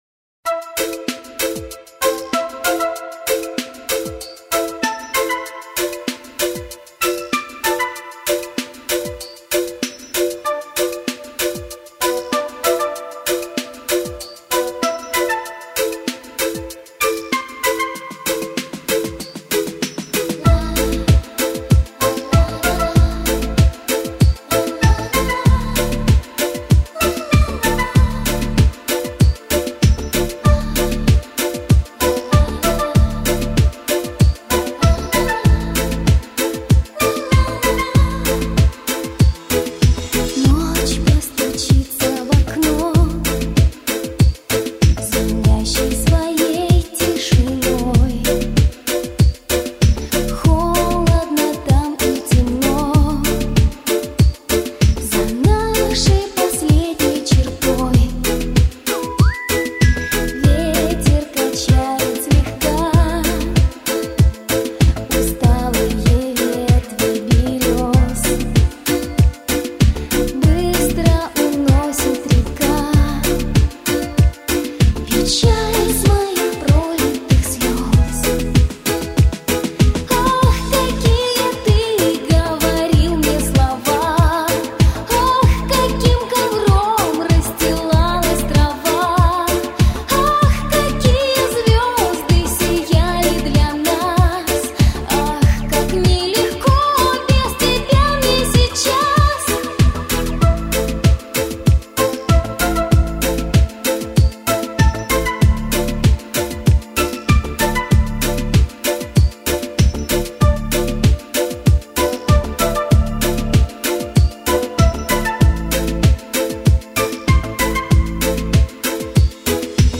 Главная » Файлы » Шансон